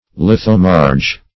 Search Result for " lithomarge" : The Collaborative International Dictionary of English v.0.48: Lithomarge \Lith"o*marge\, n. [Litho- + L. marga marl.]